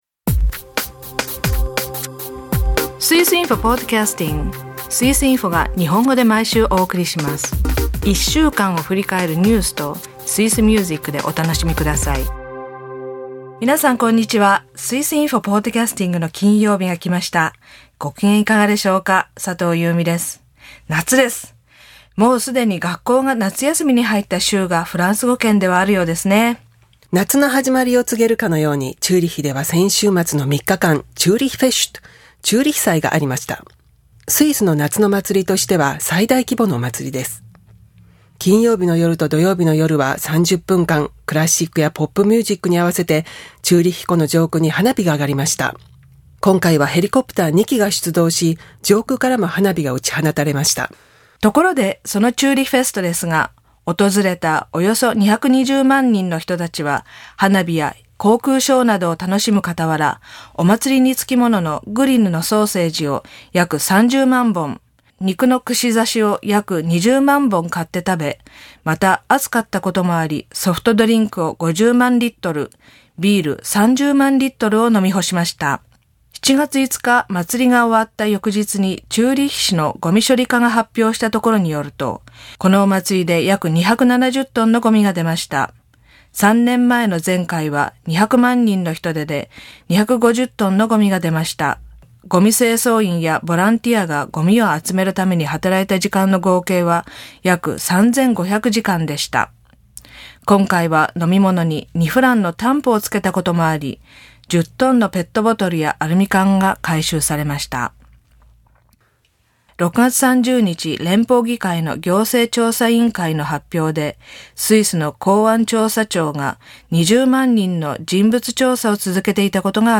朗読ではウーリたちがベルリンに到着します。